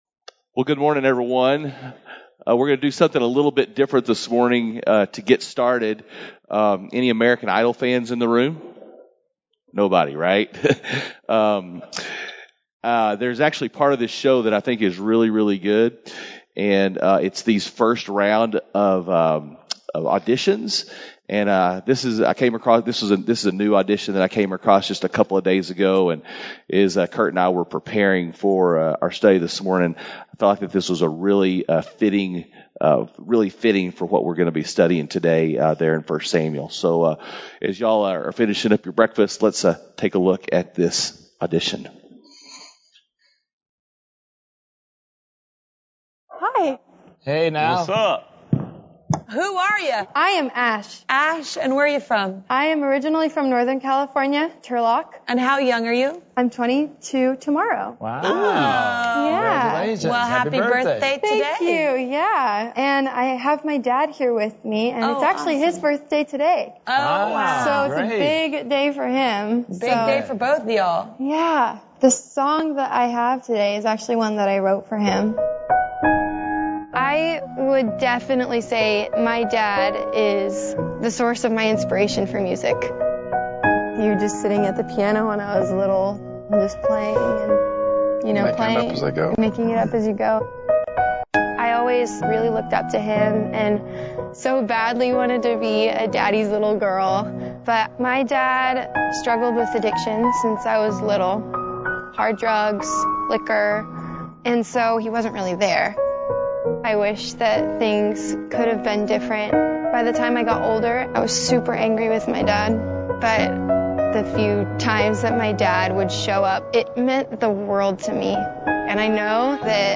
Men’s Breakfast Bible Study 3/9/21
Mens-Breakfast-Bible-Study-3_9_21.mp3